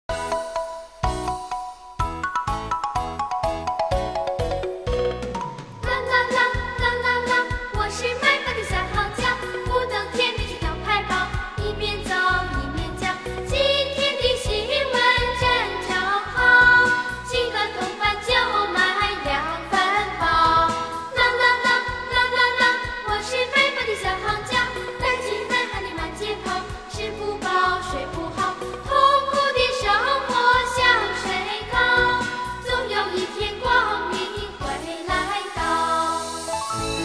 低音质试听： (WMA/128K)